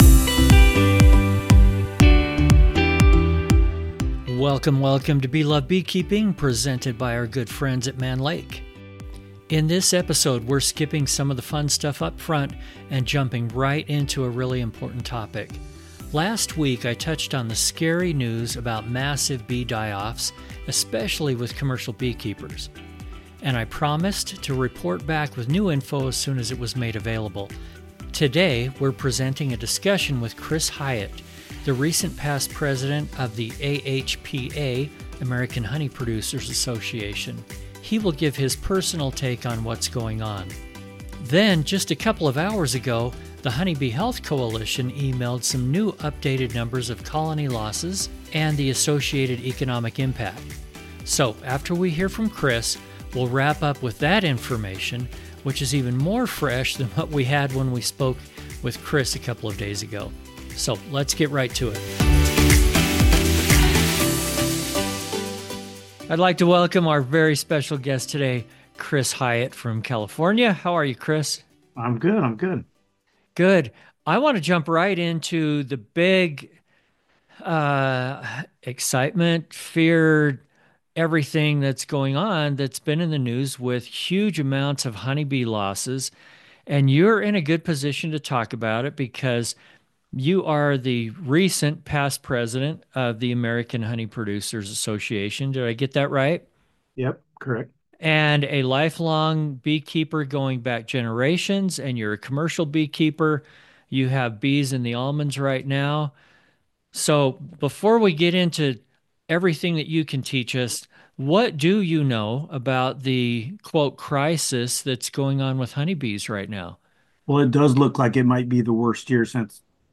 The topic of conversation is this winter's severe colony loss, especially with commercial beekeepers. We discuss the amount of losses, possible causes and prevention techniques.